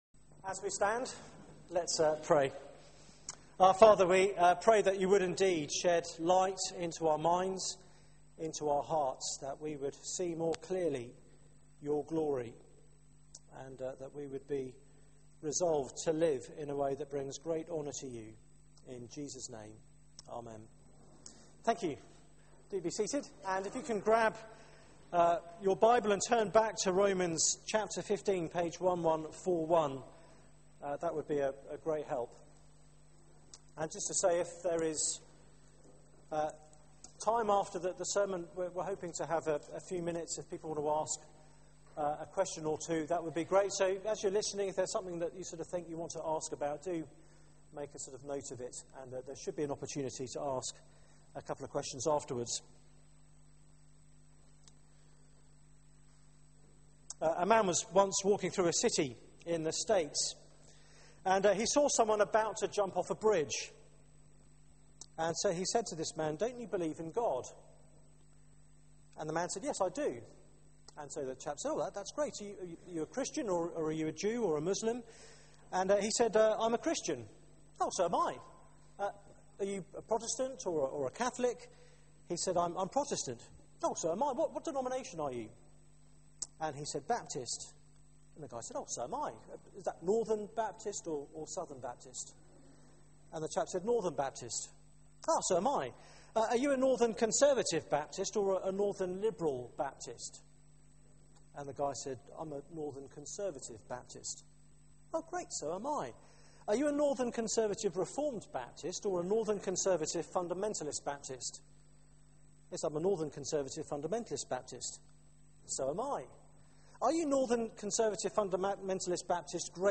Media for 6:30pm Service on Sun 14th Oct 2012 18:30 Speaker
Series: The Christian Life Theme: Unity in the church Sermon